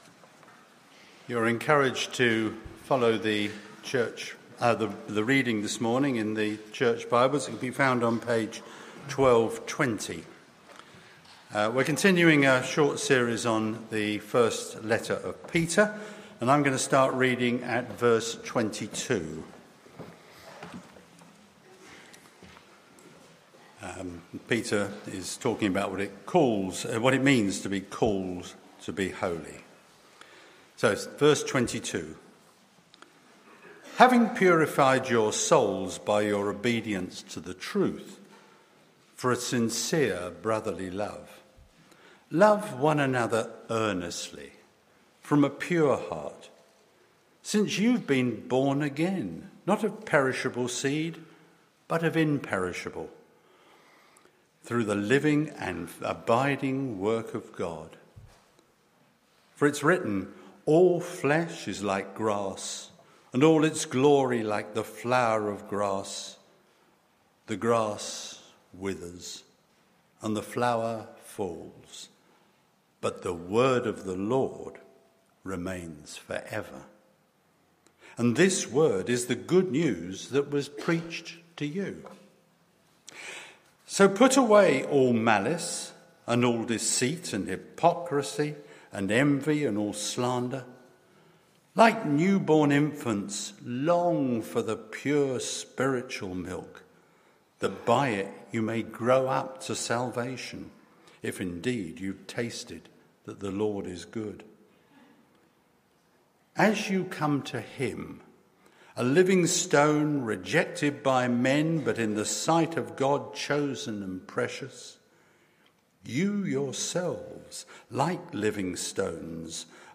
Media for Morning Meeting on Sun 12th Jan 2025 10:30 Speaker
Sermon - Audio Only Search media library...